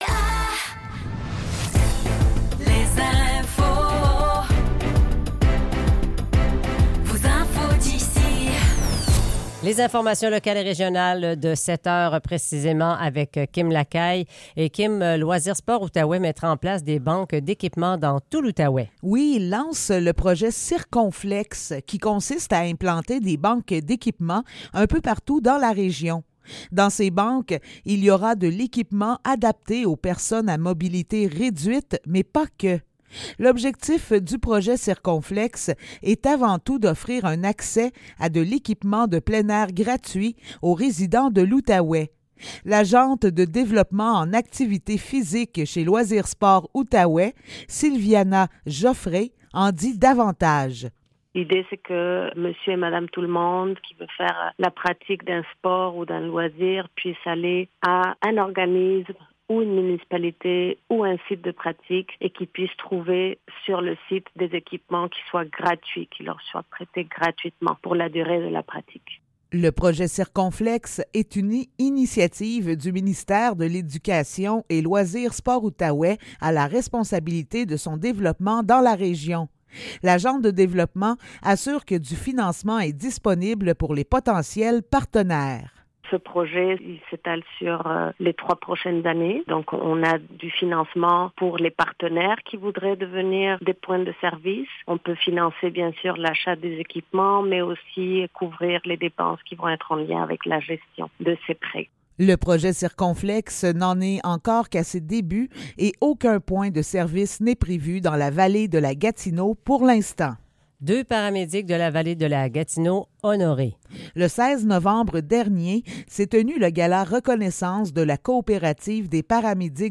Nouvelles locales - 13 décembre 2023 - 7 h